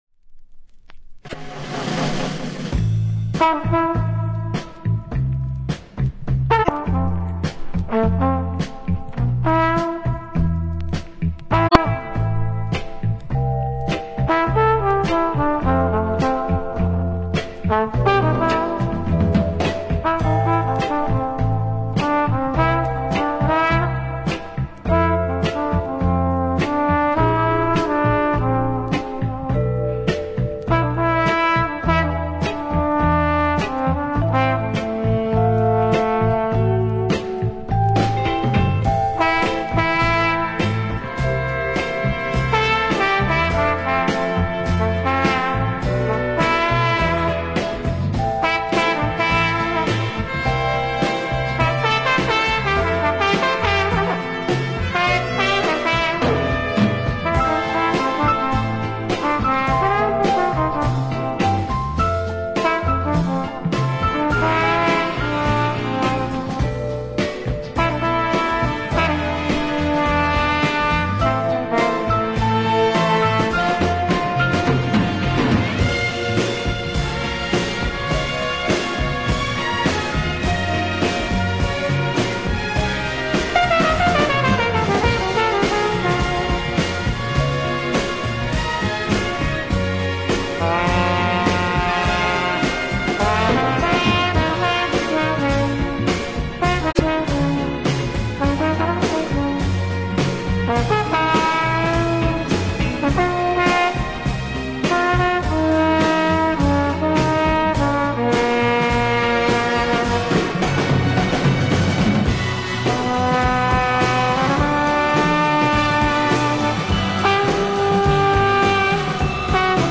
flugelhorn